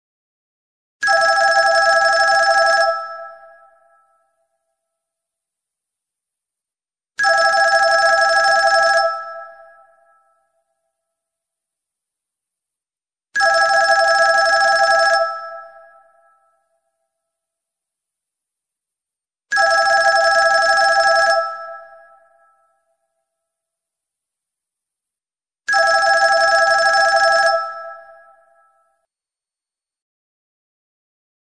Categories Electronic Ringtones